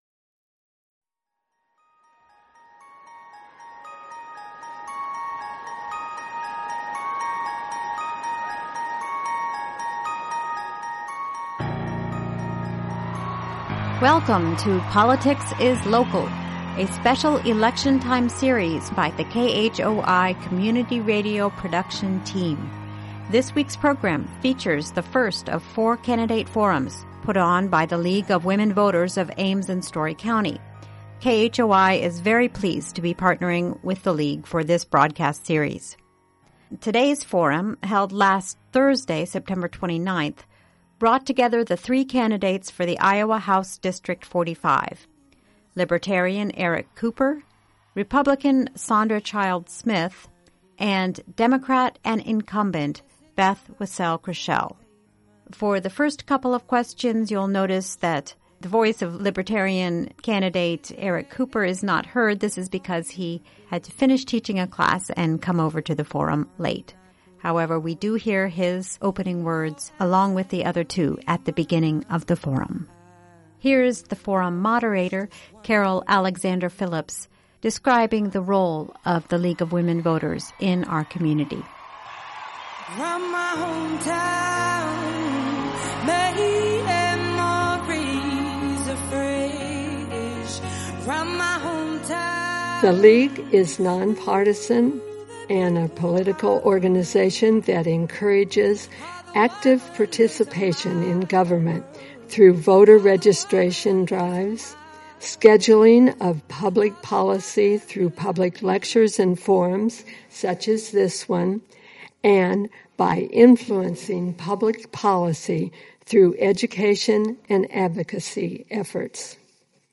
The September 29 League of Women Voters debate among candidates for House District 45